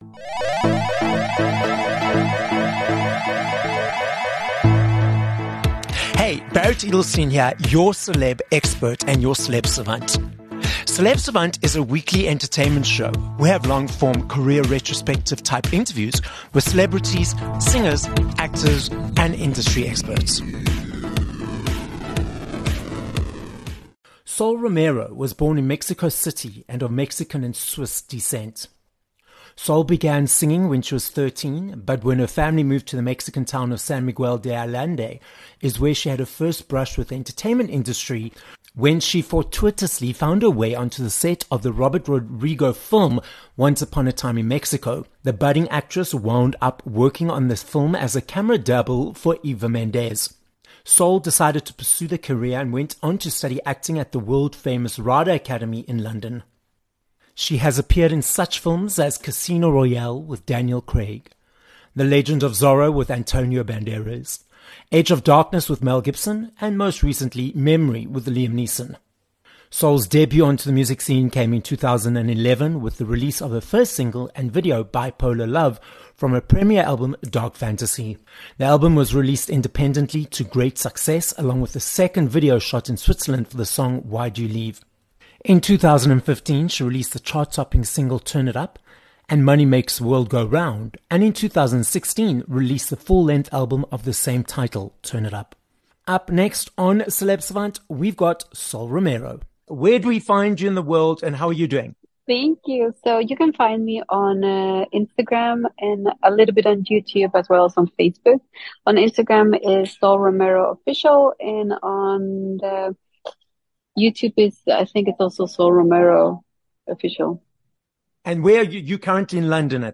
16 Jan Interview